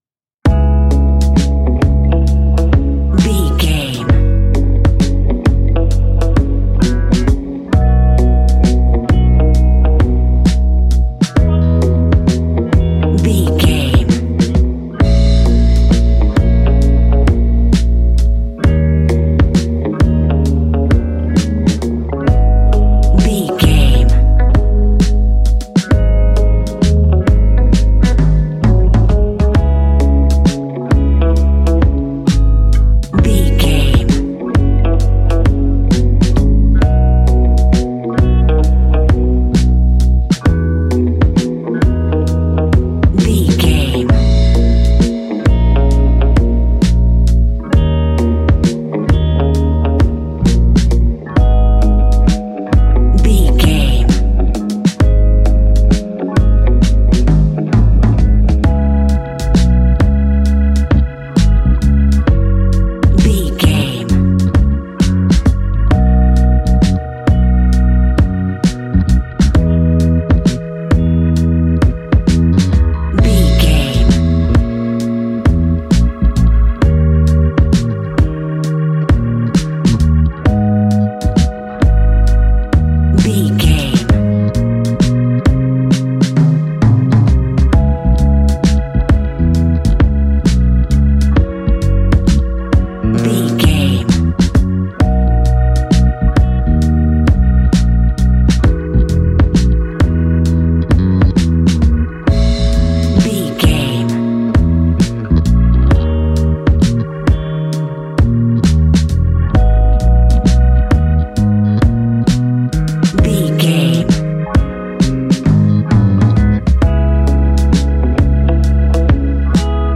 Ionian/Major
G♭
chilled
laid back
Lounge
sparse
new age
chilled electronica
ambient
atmospheric
morphing
instrumentals